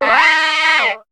Cri de Tiboudet dans Pokémon HOME.